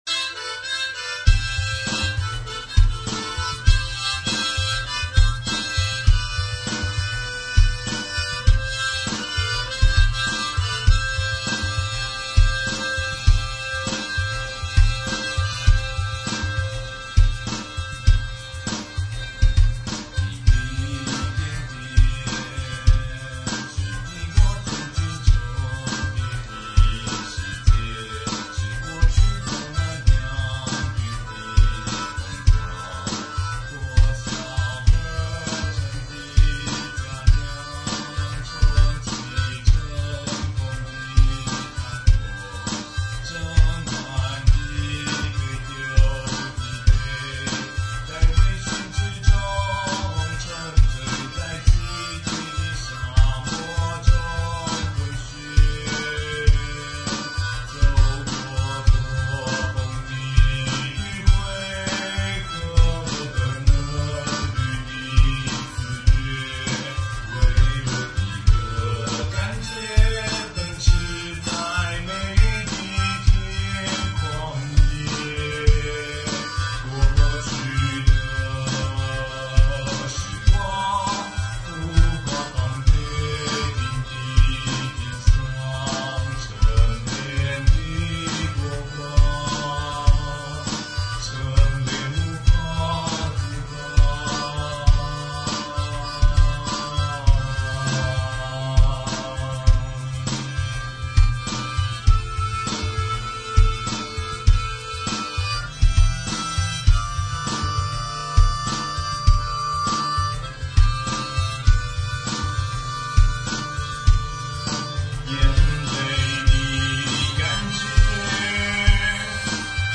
放在這邊的是用GarageBand做出來的，而用GarageBand做這個東西來玩也沒有什麼有意義的動機，純粹就是突然想要玩GarageBand而已。伴奏很簡單，只有口琴，因為我現在手邊就只有這一項樂器而已，我又不太想要用GarageBand內建的Loop。